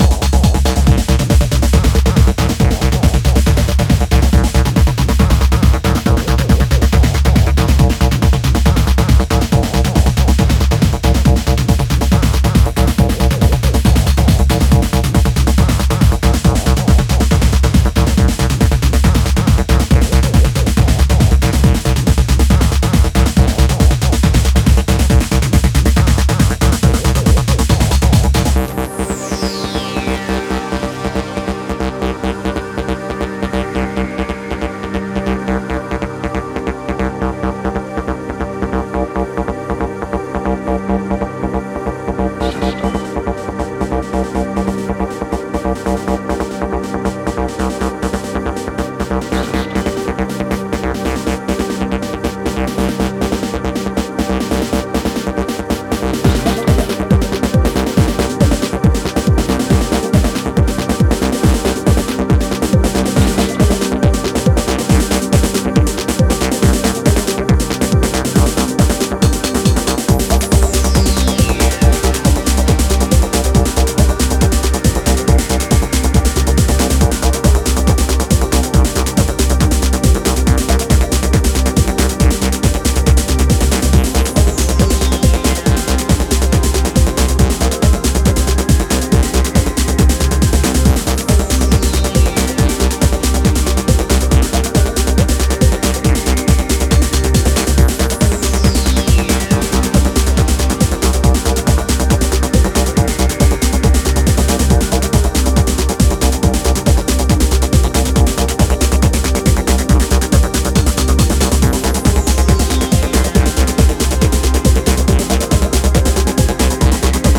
ハードなグルーヴに忘我的なアシッド、オプティミスティックなシンセが渾然一体となった